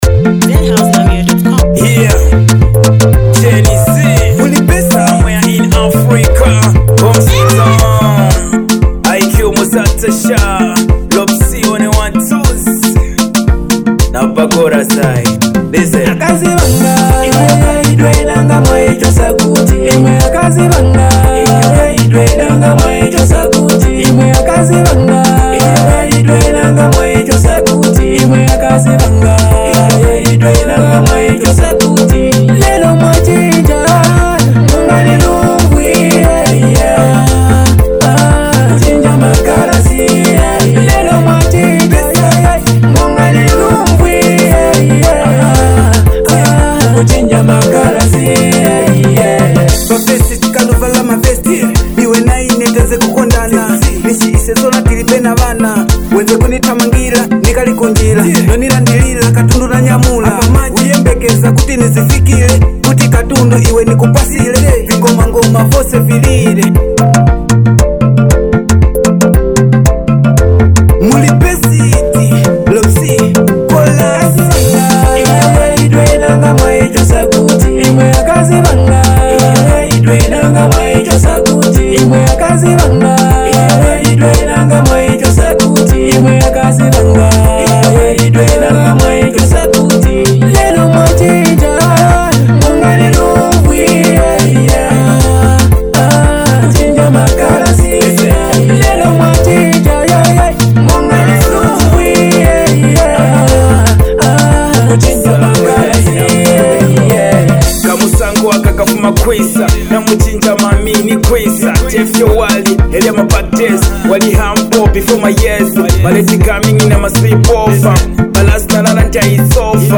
Smooth, emotional, and packed with real-life vibes.